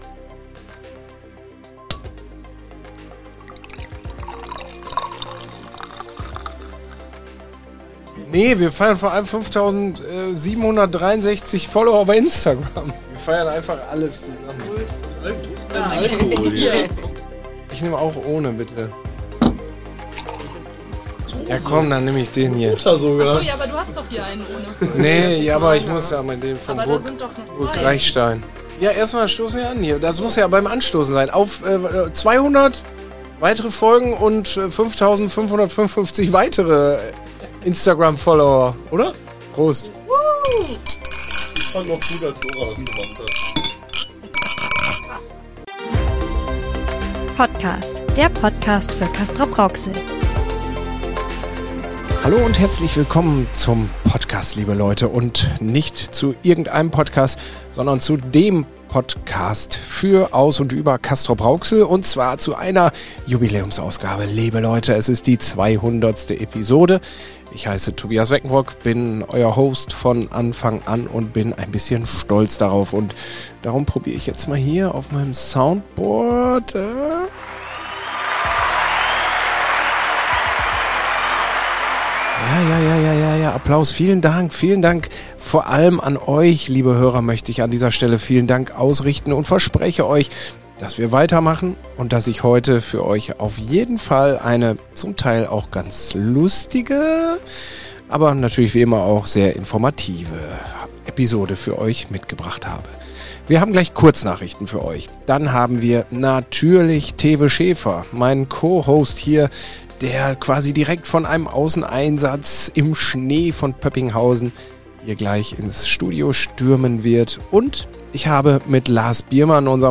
Wir haben unsere 200. Episode aufgenommen und stoßen gemeinsam an. Dabei sprechen wir nicht nur über knallharte News aus Castrop-Rauxel, über die Hintergründe hinter den Nachrichten und unsere Termine im Schnee, sondern liefern auch Unterhaltsames, Lesetipps - und am Ende bisher unveröffentlichtes Material aus den letzten 100 Episoden PottCAS.